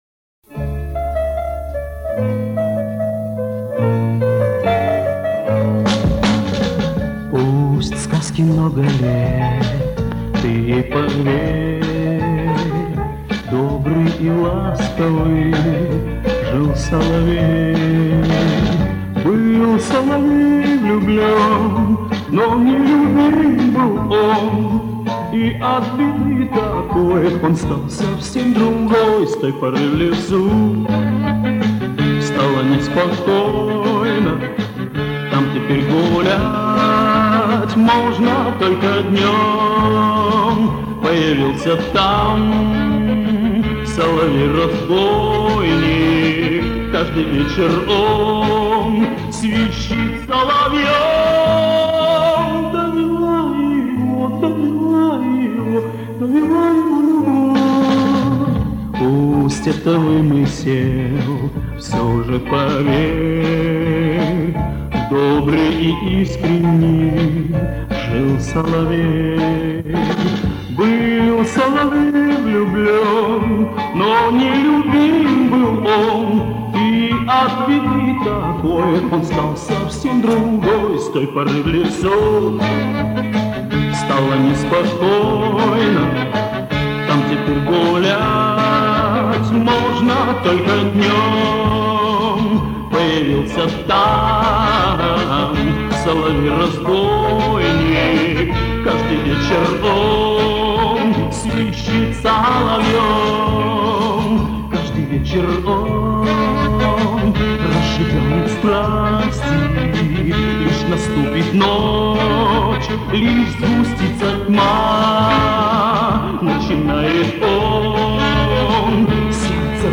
Запись с радио
поет под большой оркестр